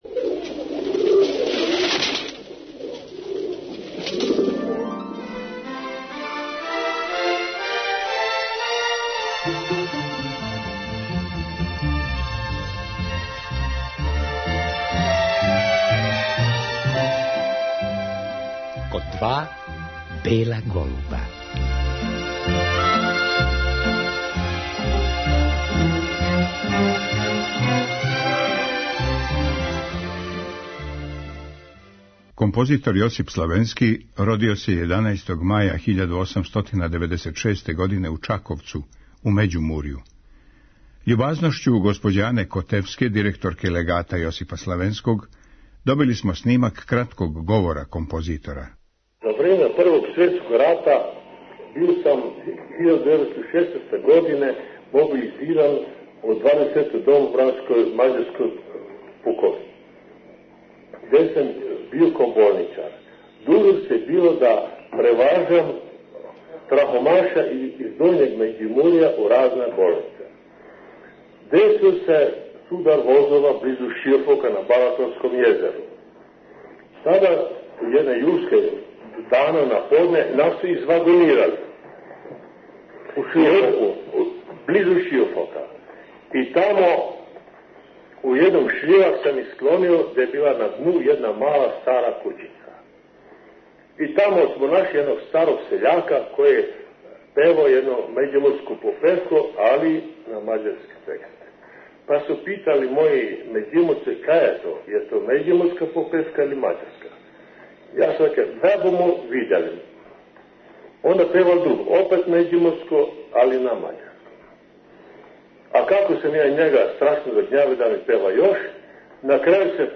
У Београду је Славенски био професор у гимназији, музичкој школи и на Музичкој академији. О свом професору говоре његови ученици и студенти